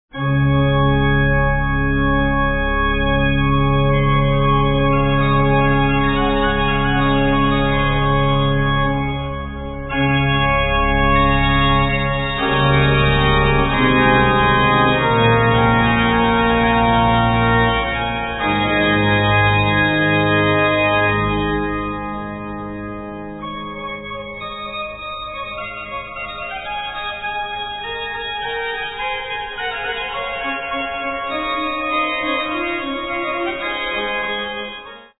Sonata for organ - 2:21